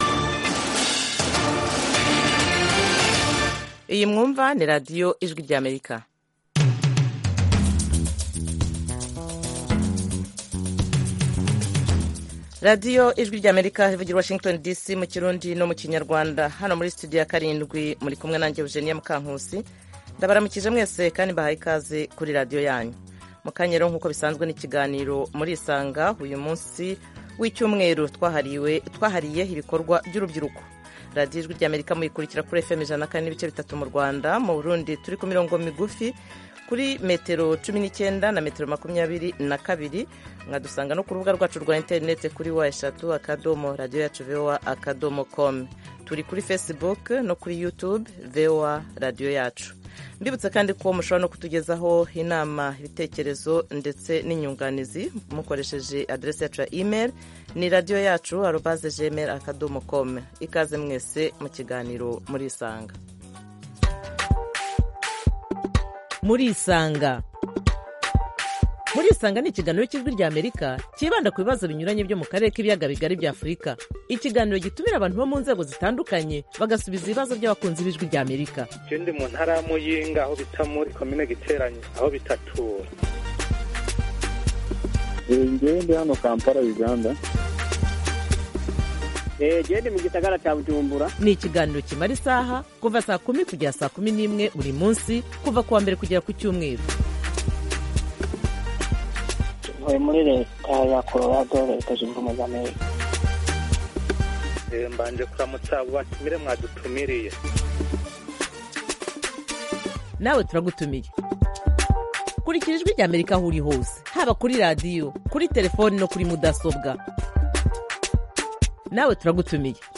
Murisanga itumira umutumirwa, cyangwa abatumirwa kugirango baganire n'abakunzi ba Radiyo Ijwi ry'Amerika. Aha duha ijambo abantu bipfuza kuganira n'abatumirwa bacu, batanga ibisobanuro ku bibazo binyuranye bireba ubuzima bw'abantu.